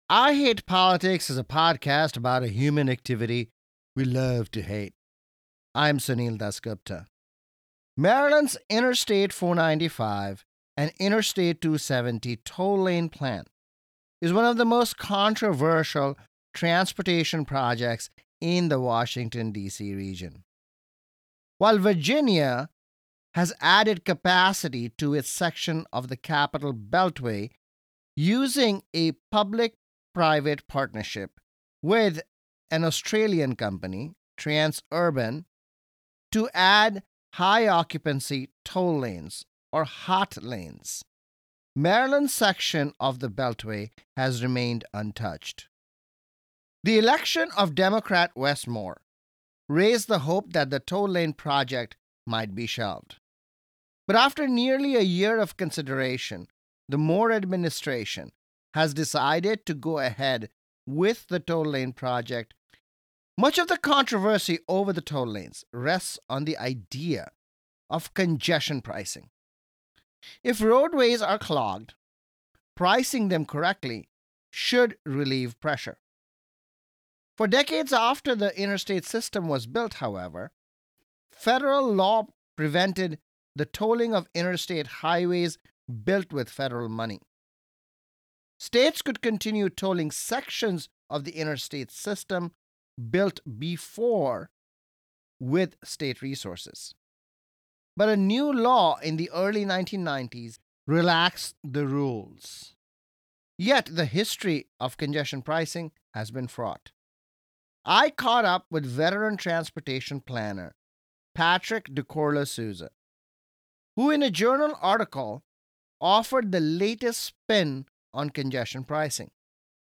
talks with transportation planner